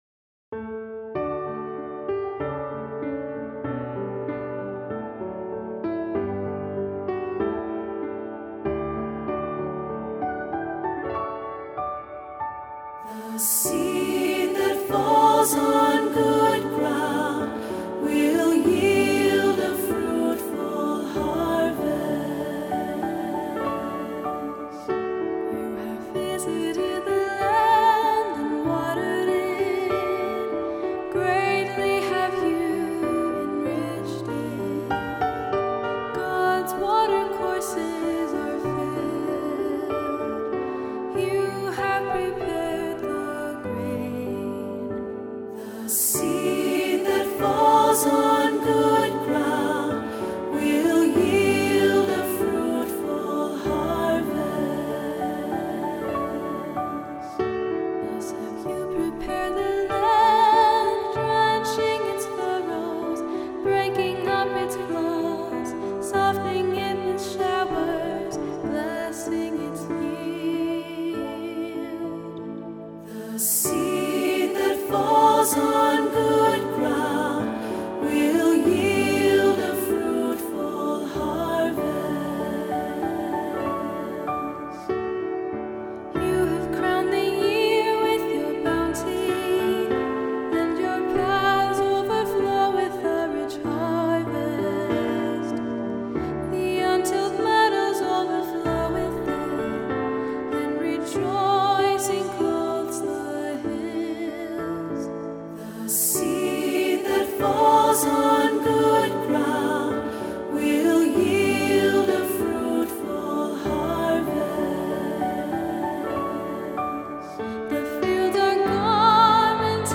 Voicing: "Three-part equal","Cantor","Assembly"